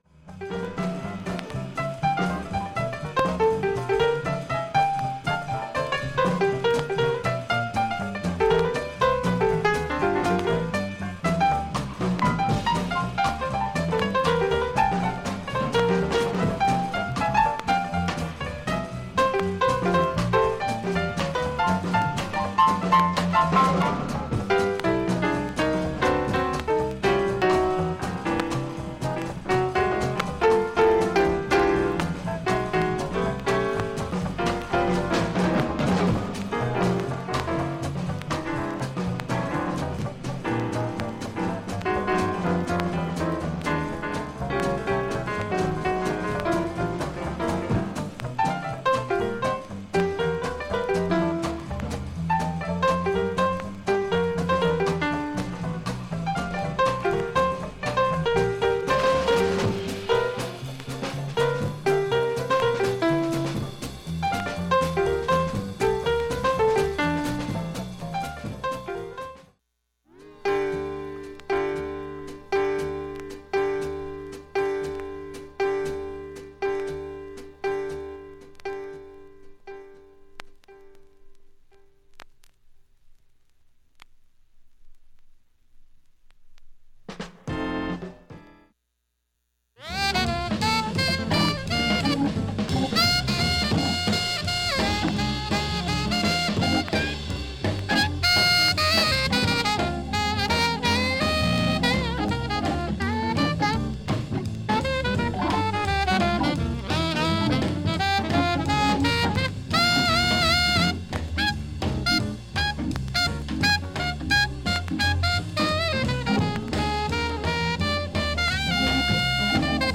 ３回までのかすかなプツが２箇所 単発のかすかなプツが５箇所
Mono